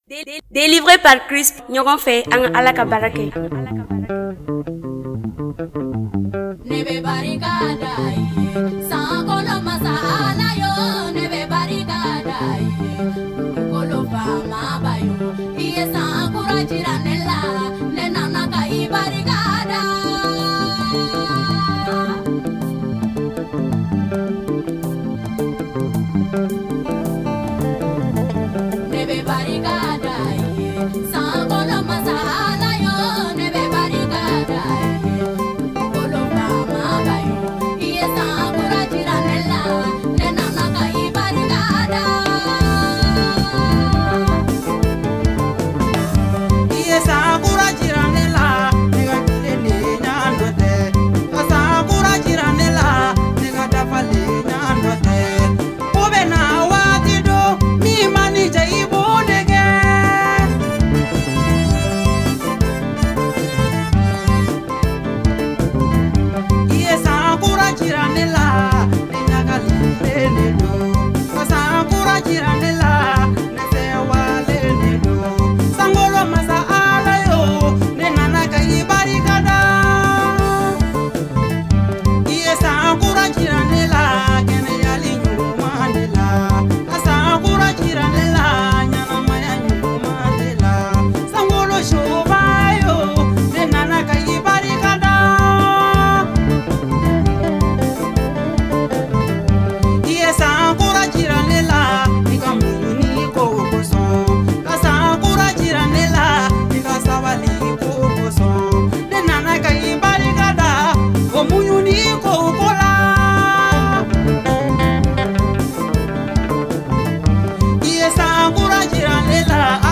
cantique